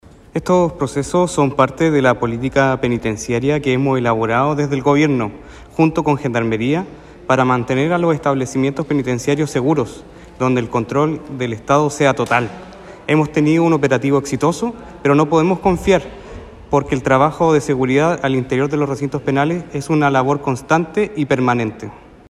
Por su parte, el seremi de Justicia y Derechos Humanos, Cristóbal Fuenzalida, explicó que mediante estas acciones de control en los recintos carcelarios tienen la fuerte intención de mantener el control estatal en estos recintos.